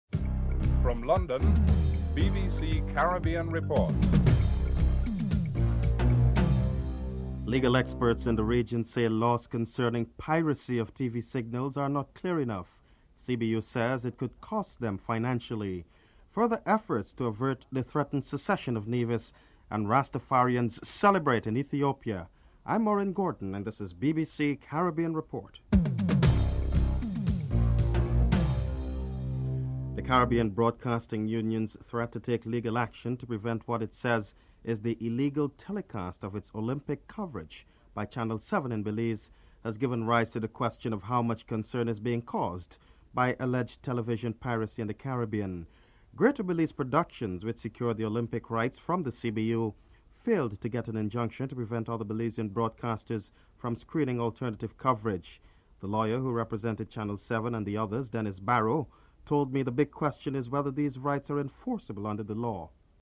The British Broadcasting Corporation
1. Headlines (00:00-00:28)